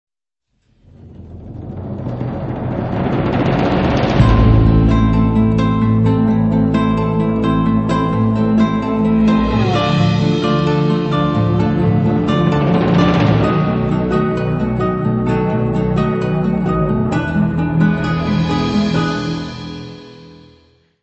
voz
baixo semi-acústico, contrabaixo
percussões, bateria, samples, programação
: stereo; 12 cm